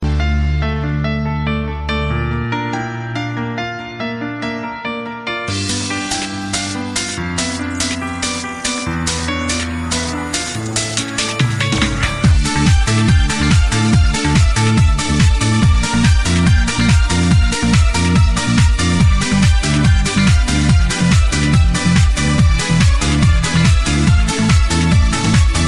8-bit